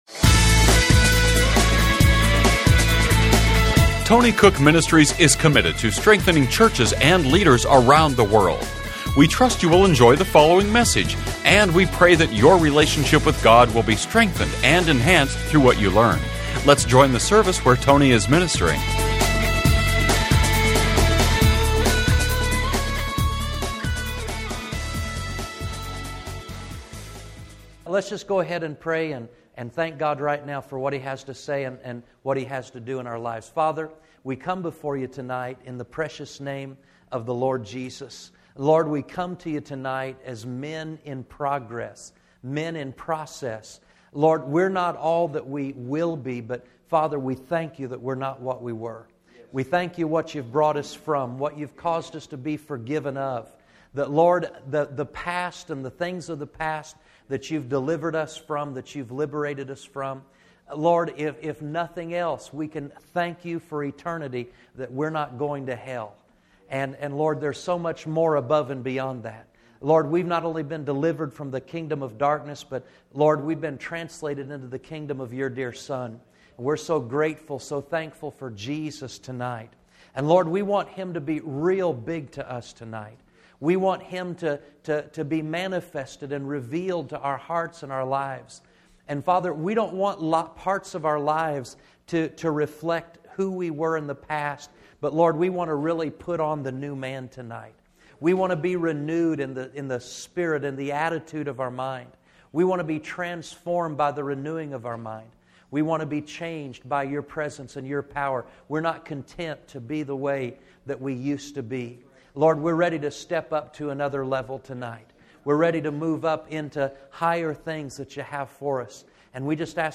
Genre: Christian Teaching.